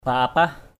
/pa-a-pah/ 1.